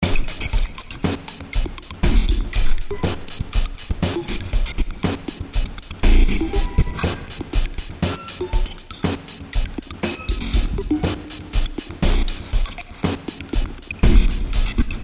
Tag: 120 bpm Glitch Loops Drum Loops 2.52 MB wav Key : Unknown